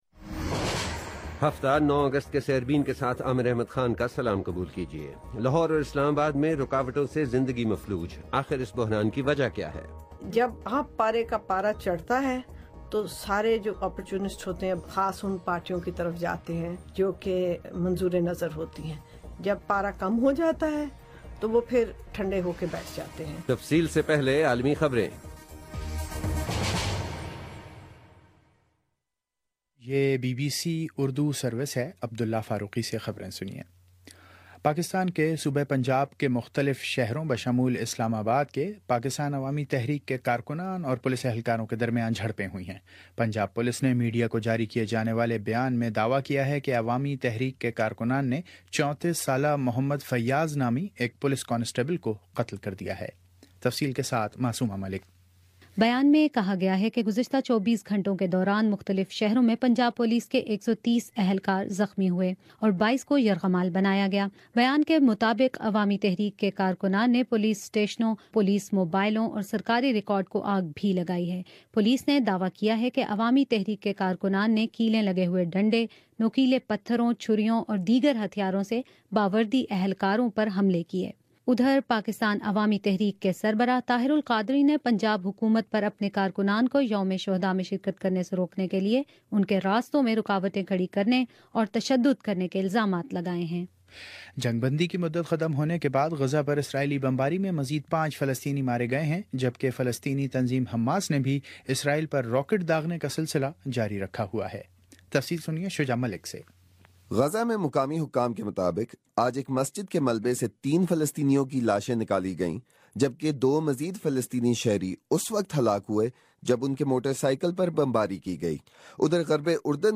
سینیچر 9 اگست کا سیربین ریڈیو پروگرام